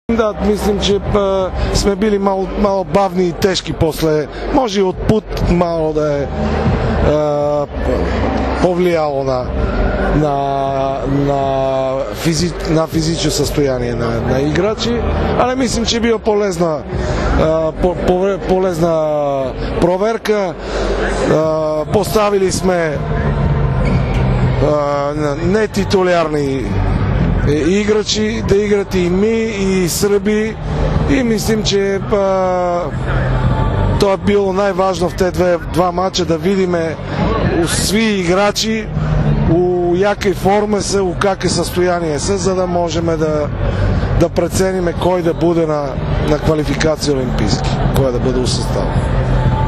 IZJAVA PLAMENA KONSTANTINOVA